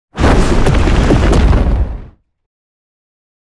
scorching-ray-001-30ft.ogg